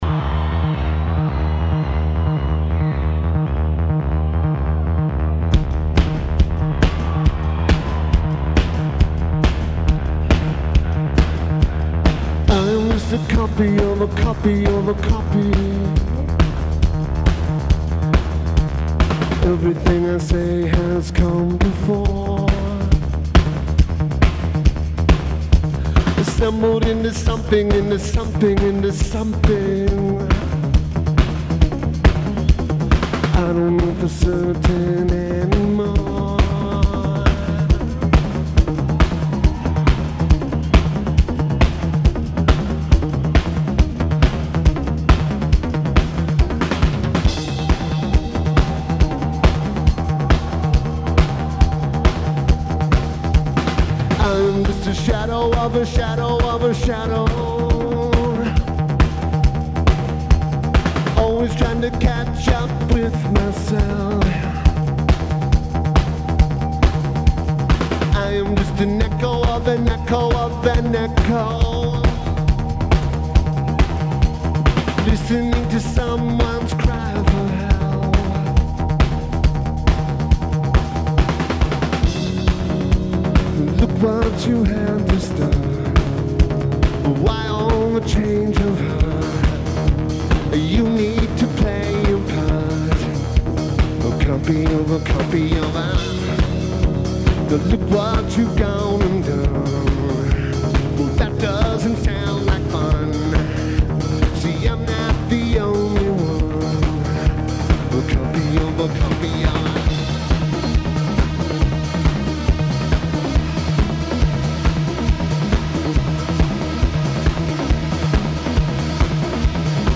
Corona Capital Festival
Drums
Guitar
Lineage: Audio - SBD (Live Stream Audio)